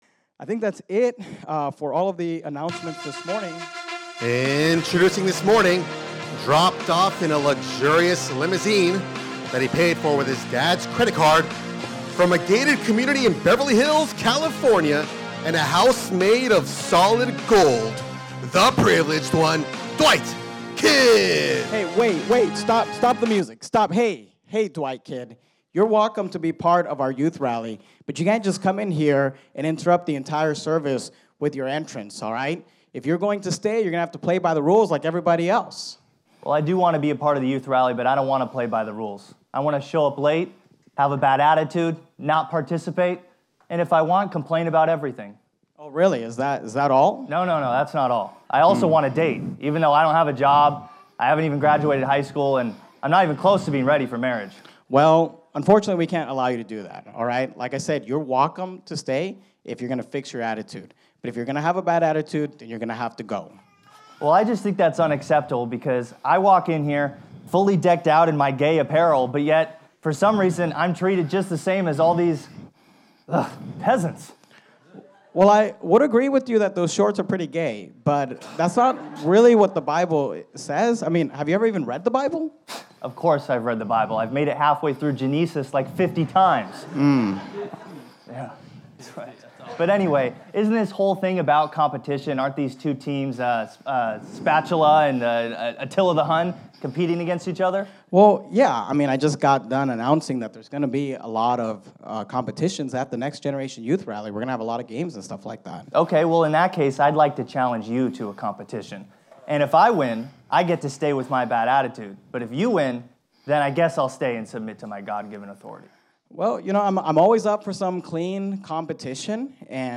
Skit #1 | The Challenge | The Next Generation Youth Rally 2023 | Banned But Not Bound
Skit #1: The Challenge